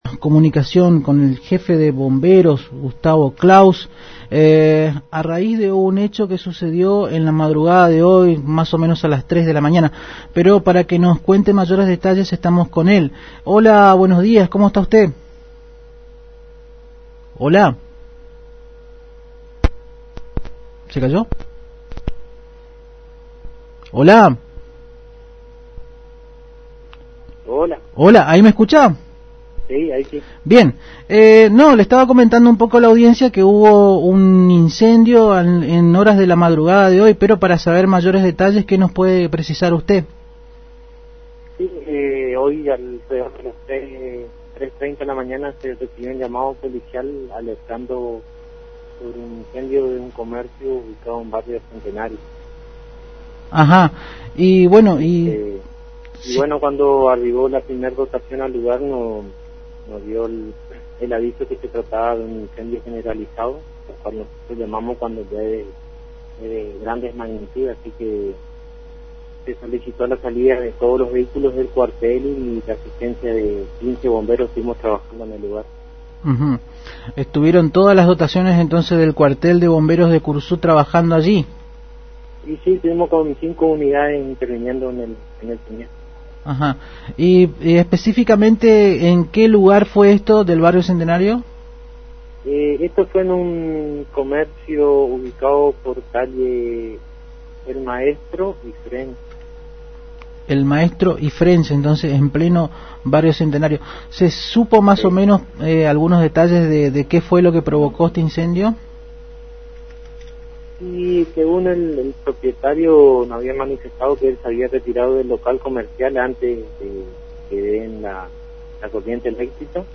AM 970 Radio Guaraní